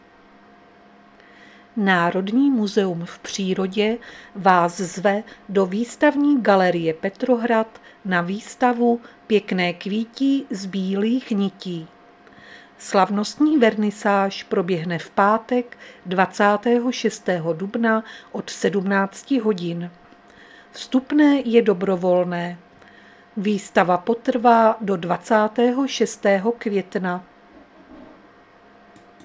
Zařazení: Rozhlas